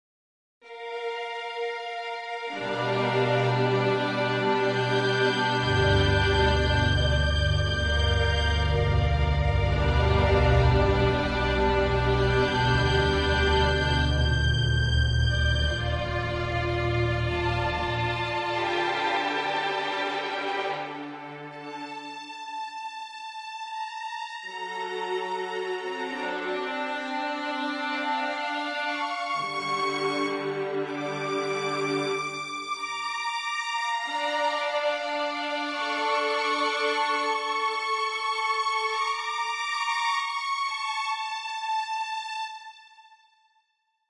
描述：不同的规模
标签： 钢琴 得分 主题 戏剧 电影
声道立体声